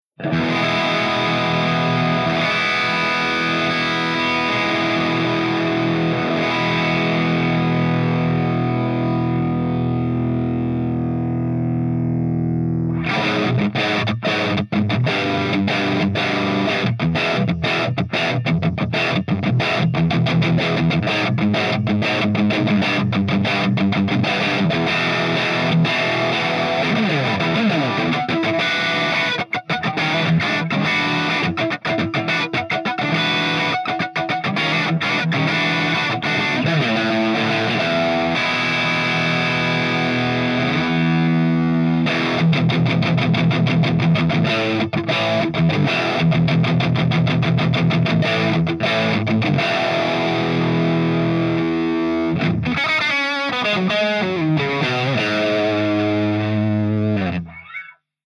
126_MARSHALLJCM800_CH2HIGHGAIN_GB_SC